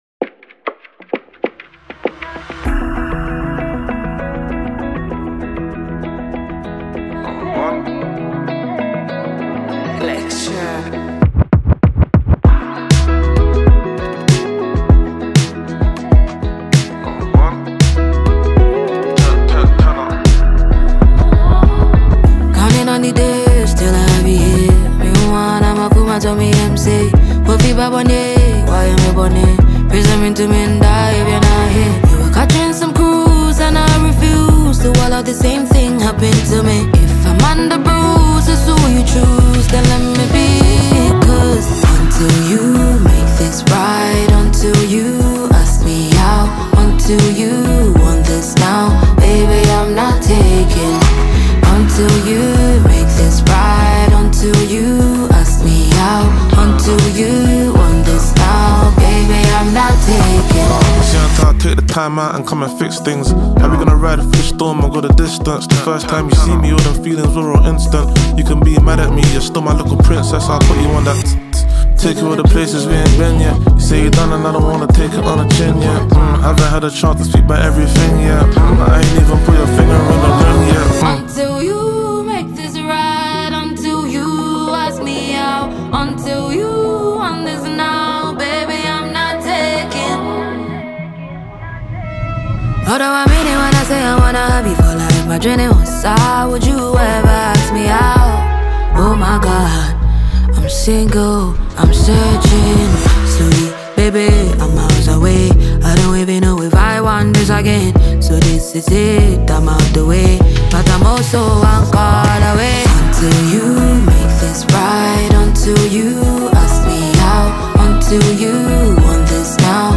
Talented Ghanaian female singer and songwriter
British-based rapper